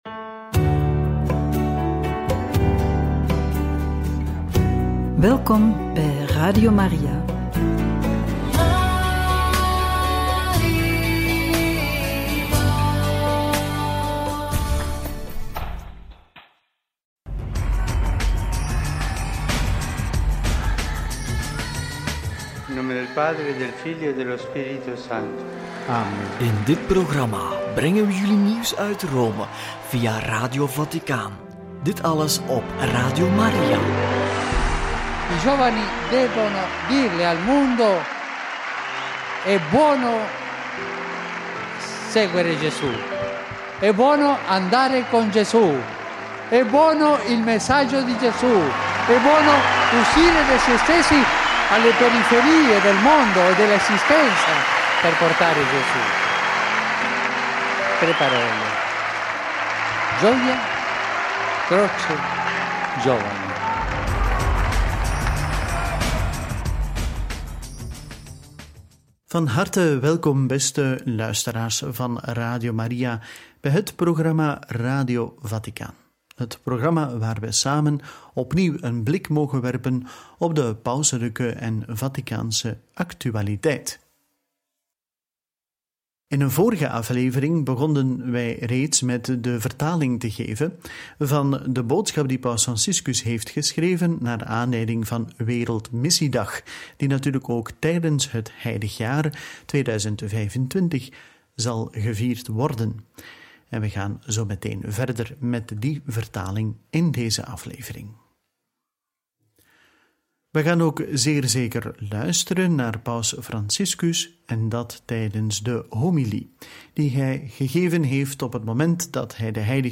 boodschap-voor-wereldmissiedag-deel-2-homilie-op-jubileum-voor-het-leger-politie-en-veiligheidspersoneel.mp3